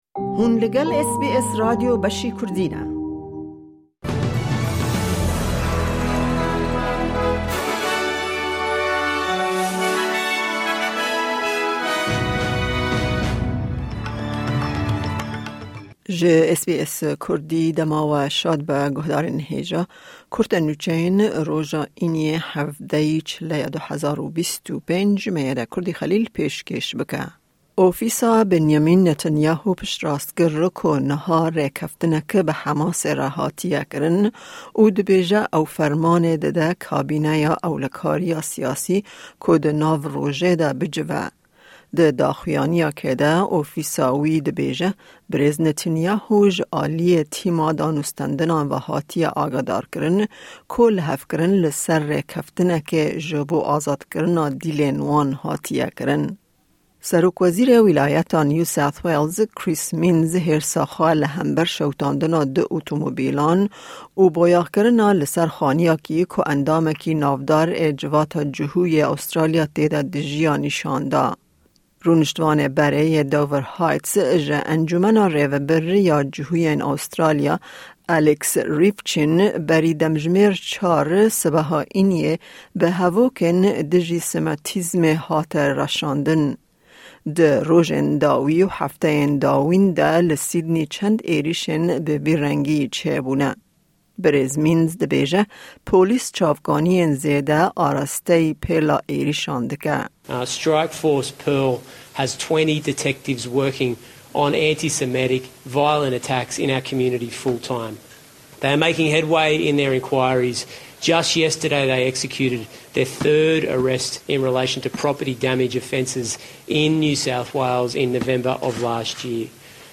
Kurte Nûçeyên roja Înî 17î Çileya 2025